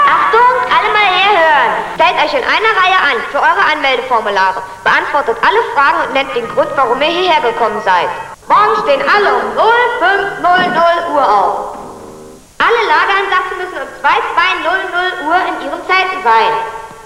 Lautsprecherstimme - ?????
cb-m3-speaker.mp3